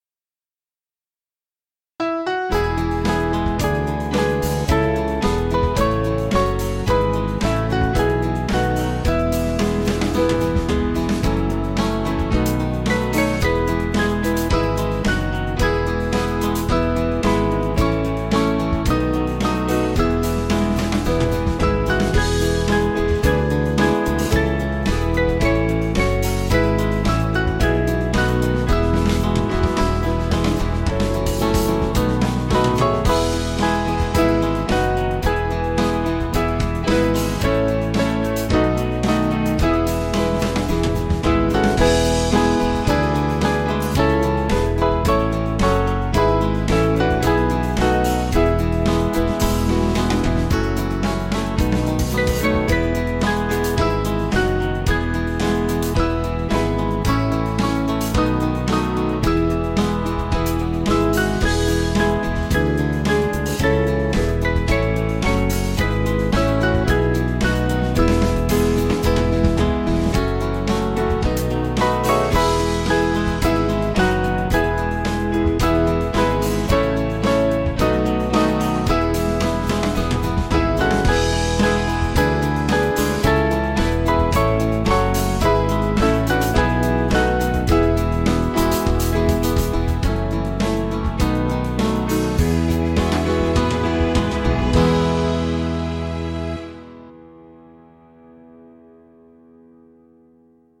German hymn
Small Band